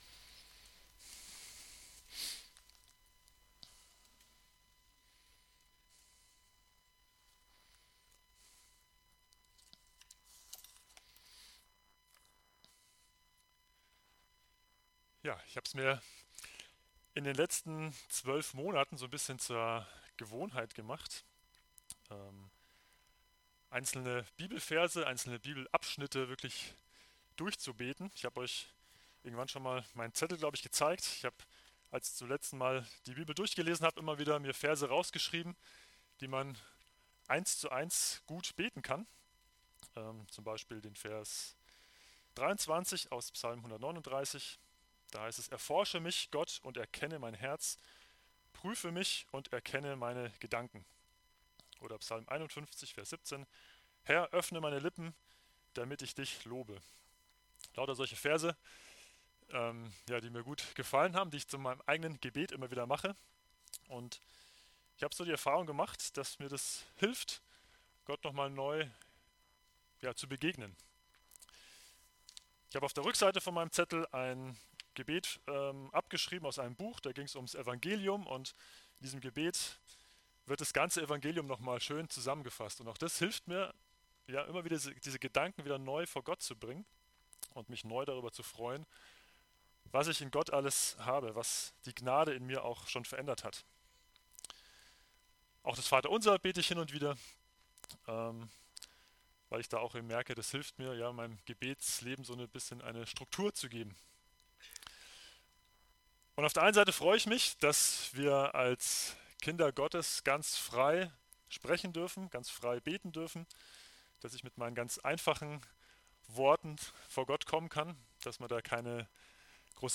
Aus der Predigtreihe: "Herr, lehre uns beten"